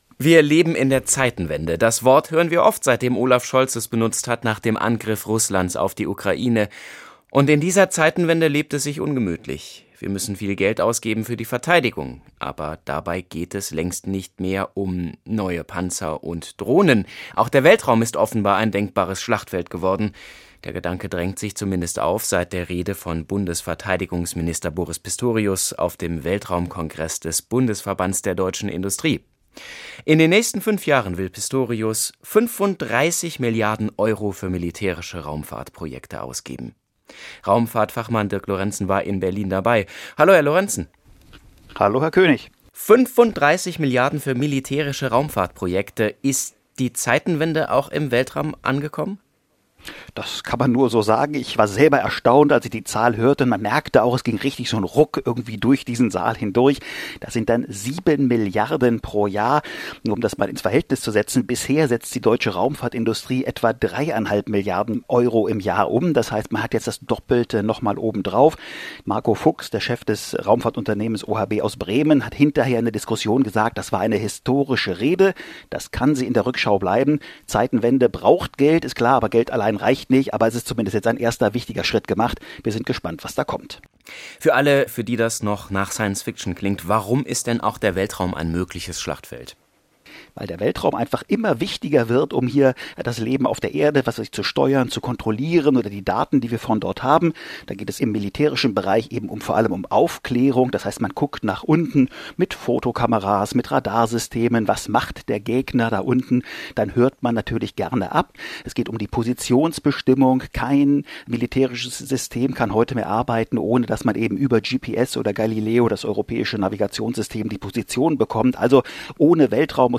Wissenschaftsjournalist und Raumfahrtfachmann